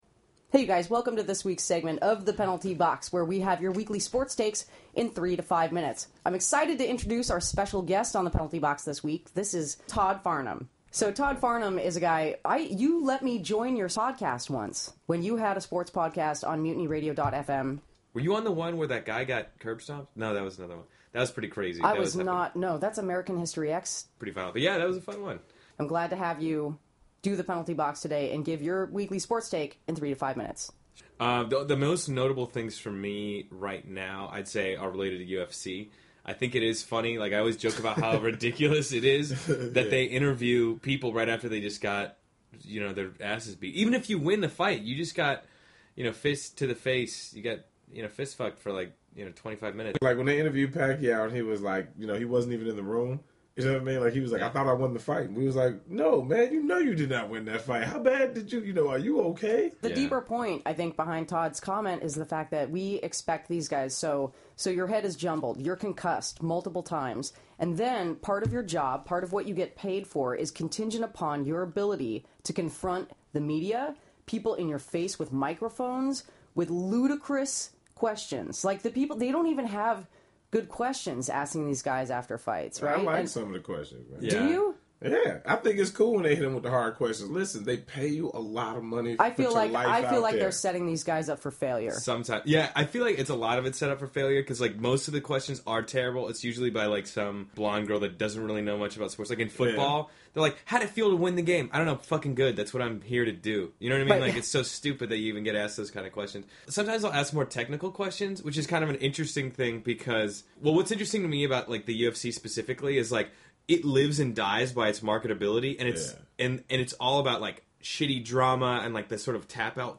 Penalty Box: Sports Takes in 2-5 Minutes